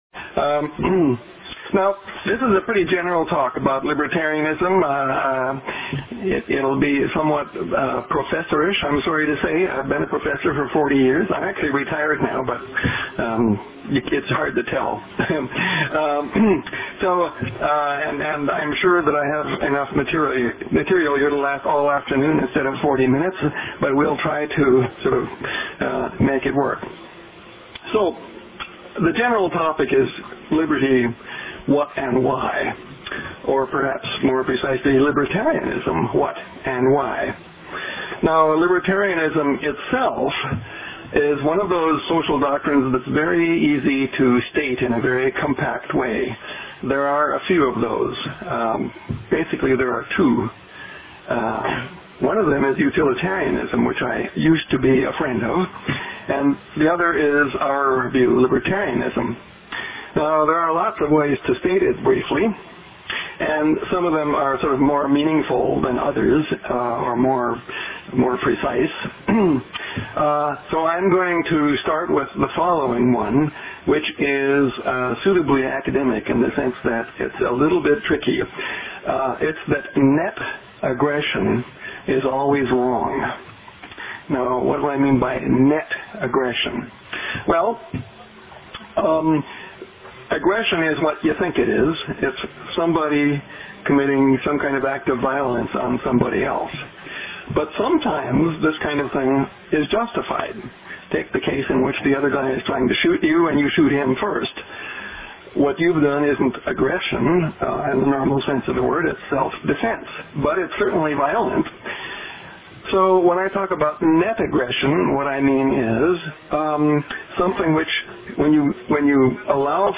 Liberty Summer Seminar. This one’s quite academic as Jan Narveson, philosophy professor at the University of Waterloo and recipient of the Order of Canada, discusses liberty.